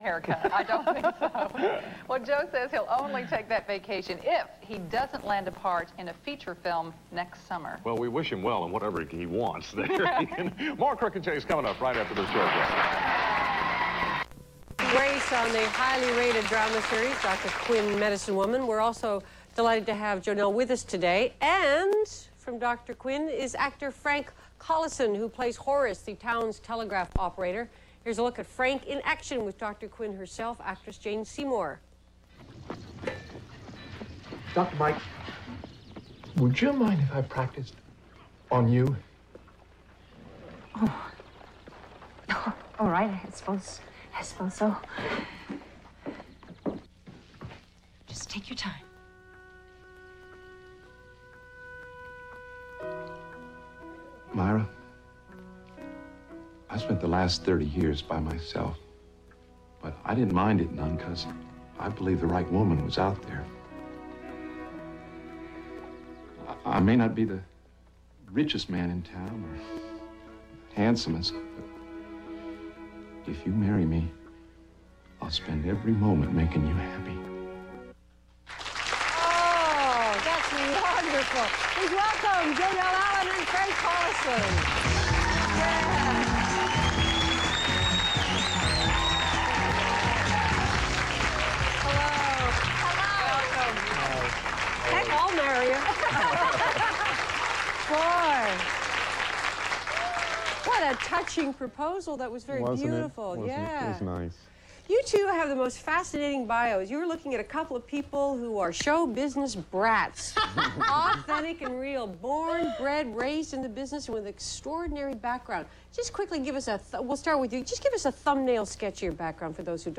What was one of the funniest things that ever happened to Jonelle Allen during the filming of Dr. Quinn Medicine Woman? Listen as she reveals all in this fascinating audio interview